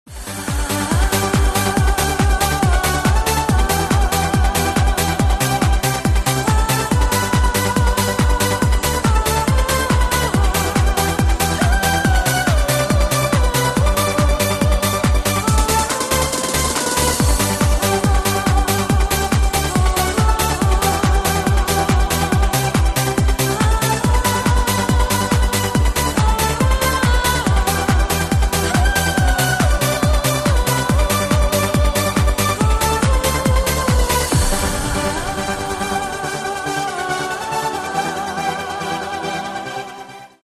• Качество: 128, Stereo
громкие
красивые
женский голос
dance
Electronic
электронная музыка
спокойные
без слов
Trance
Euro House